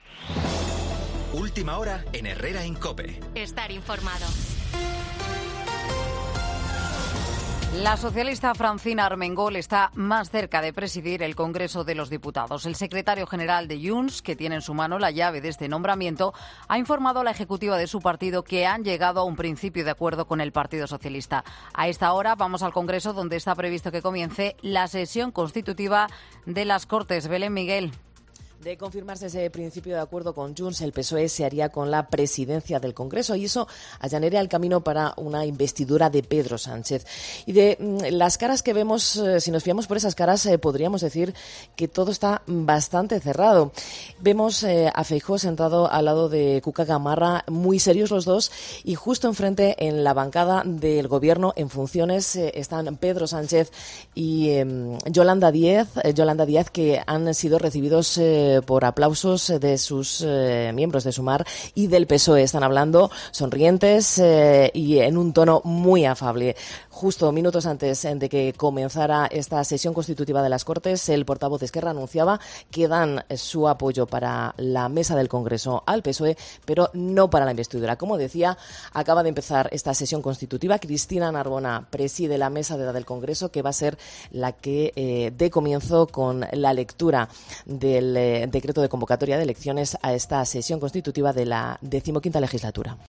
crónica
desde el Congreso